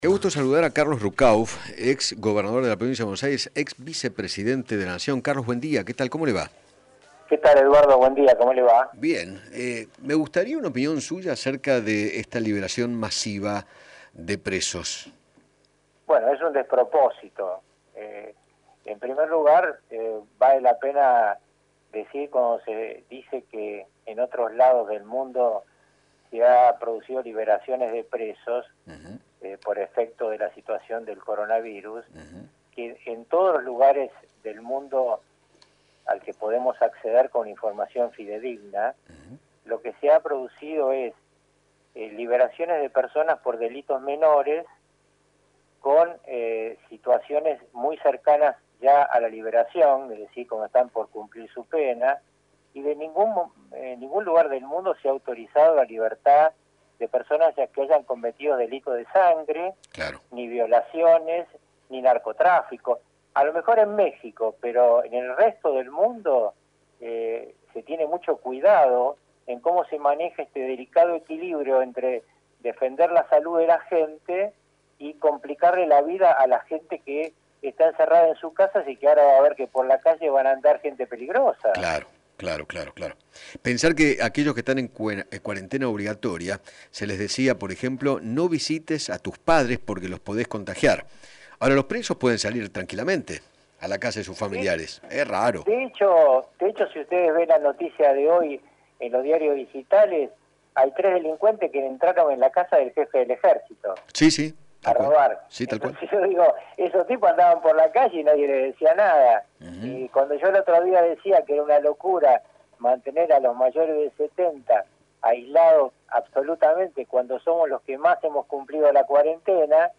Carlos Ruckauf, ex vicepresidente de la Nación, dialogó con Eduardo Feinmann sobre la liberación masiva de presos por riesgo sanitario en estos últimos días  y consideró que “es un despropósito”.